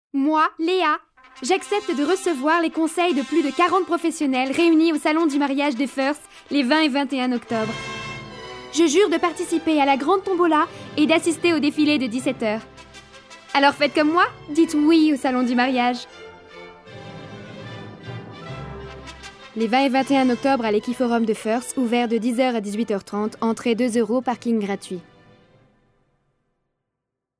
Annonce Salon du Mariage de Feurs